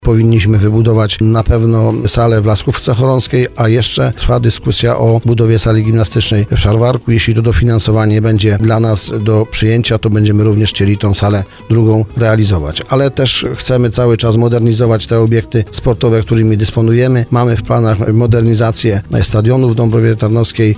Plan zakłada, że w tym roku hala sportowa z boiskiem wielofunkcyjnym zostanie oddana do użytku – mówi burmistrz Dąbrowy Tarnowskiej Krzysztof Kaczmarski.